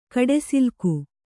♪ kaḍesilku